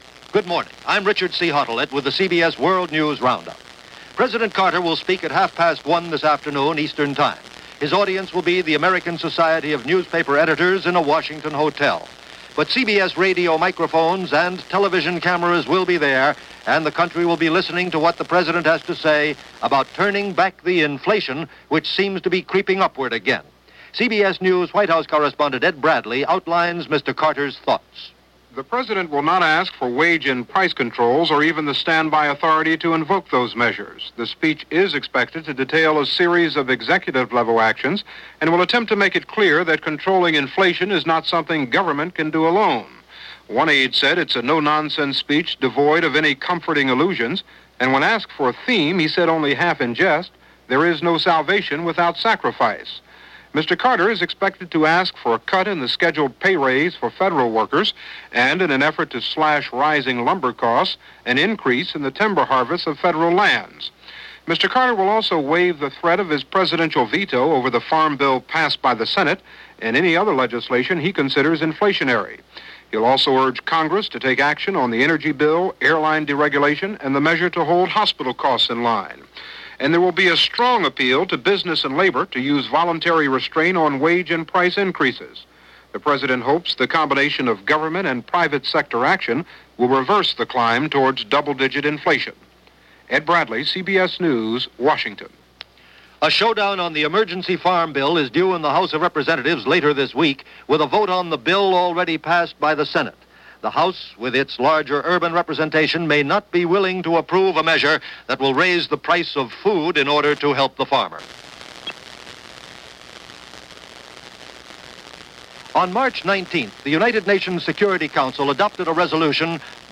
And that’s a small slice of what went on, this April 11, 1978 as reported by The CBS World News Roundup.